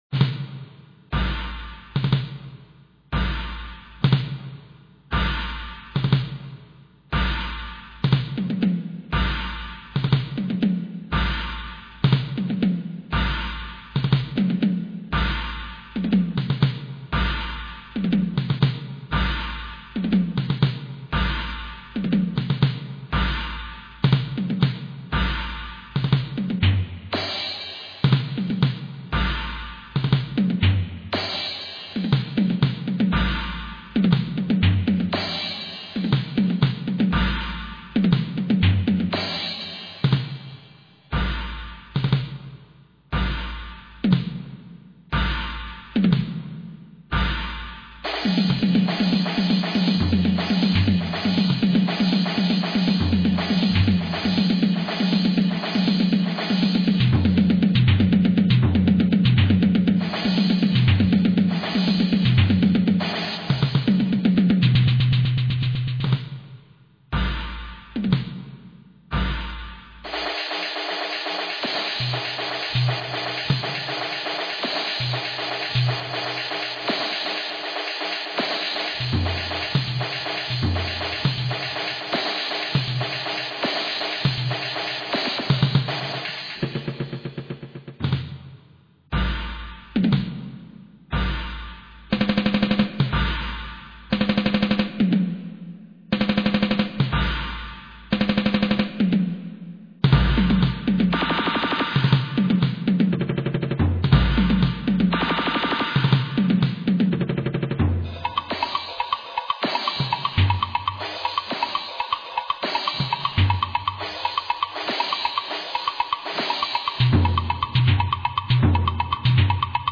Percussion instruments
Safari MMXIV, for percussion (one to three percussionists)
In this short piece, the percussion ensemble sounds as animals hastily running in the African savannah.